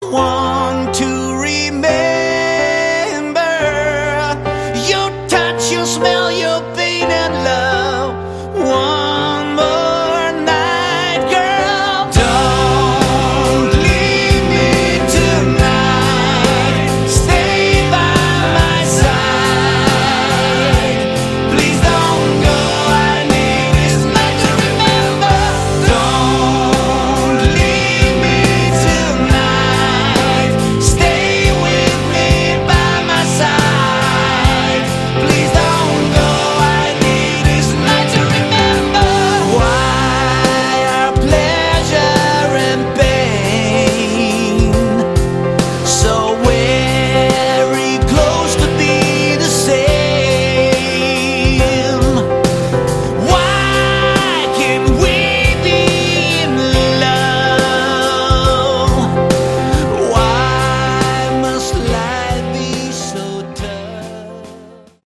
Category: Hard Rock
bass
lead guitar
rhythm guitar
keyboards
drums
lead vocals